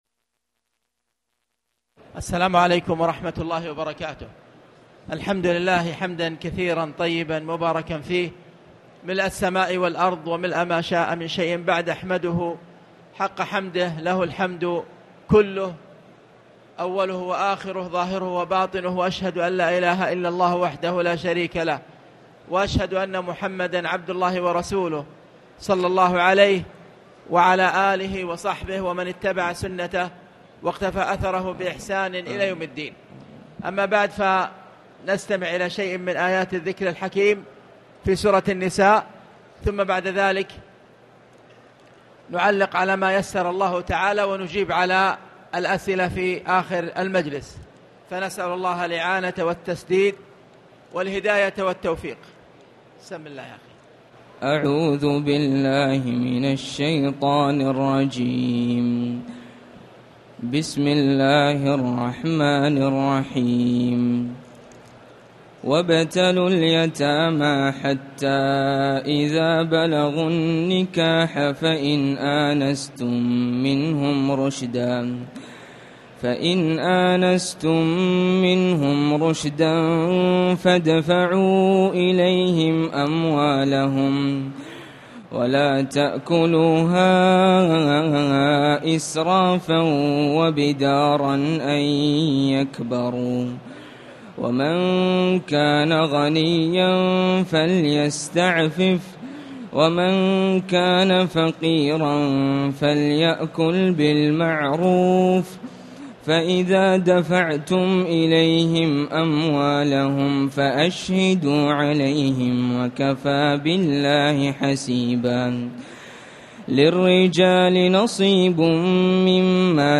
تاريخ النشر ٢٦ رمضان ١٤٣٨ هـ المكان: المسجد الحرام الشيخ